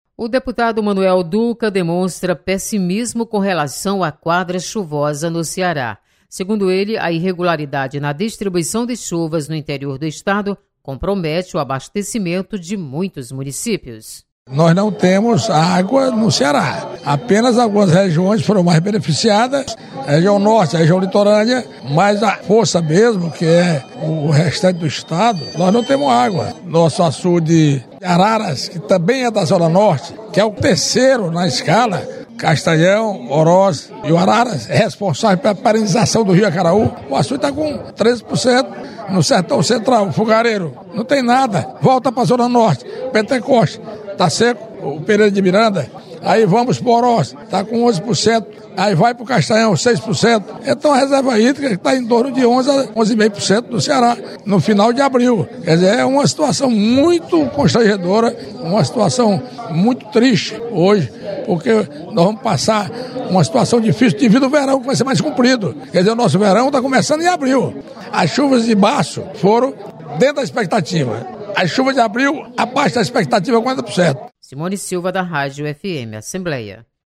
Irregularidade na quadra chuvosa desperta preocupação de parlamentares. Repórter